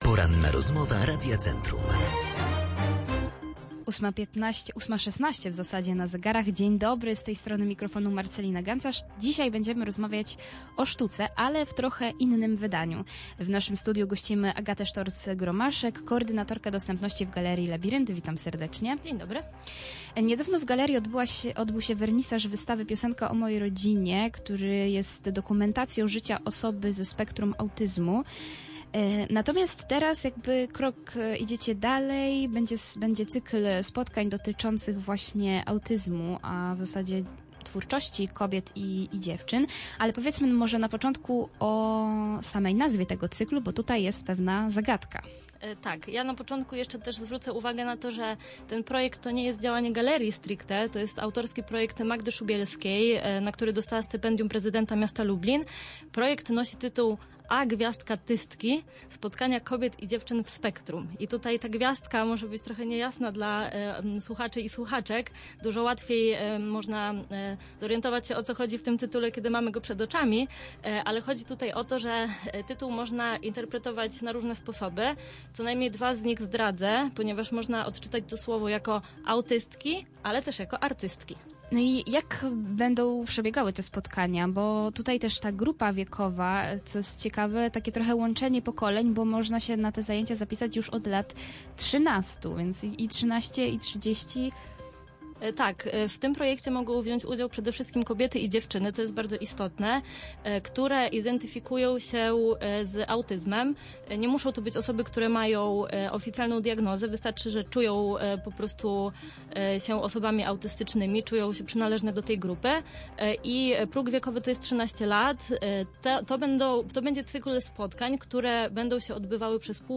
Więcej na ten temat usłyszeliśmy podczas Porannej Rozmowy Radia Centrum.
Cała rozmowa znajduje się poniżej.